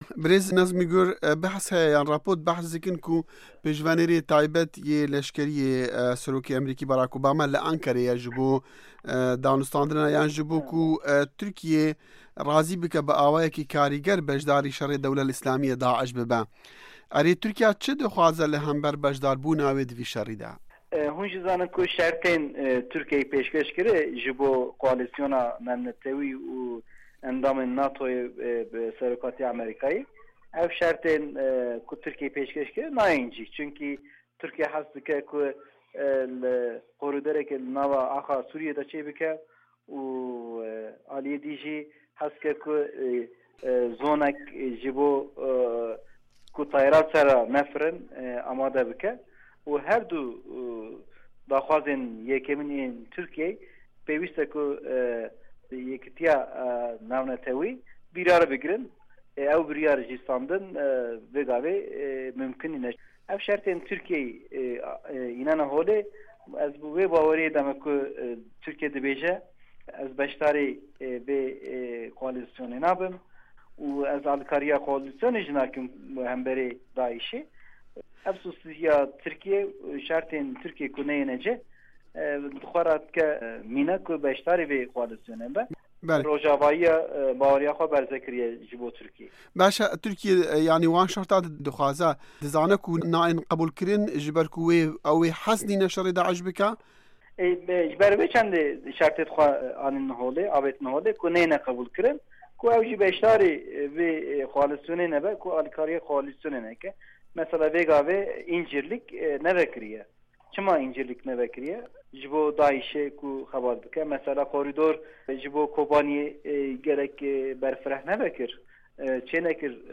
Nezmî Gur